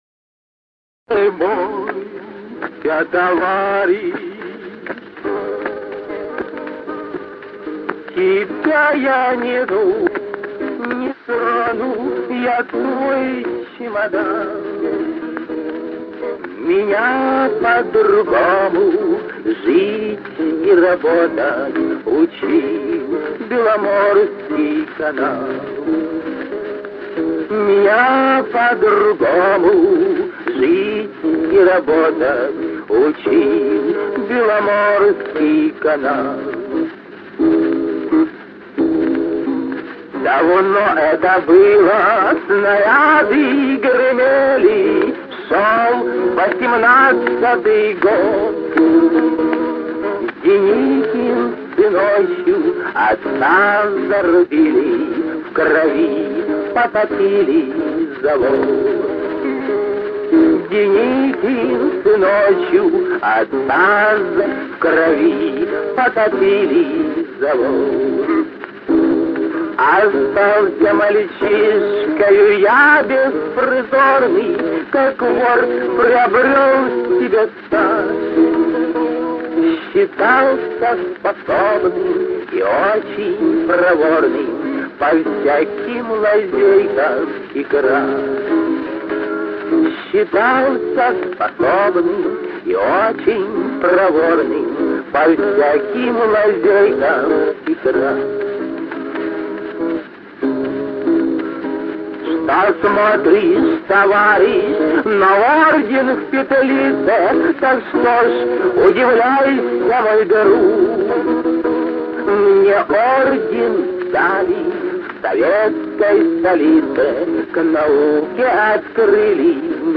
концовка немного сжёвана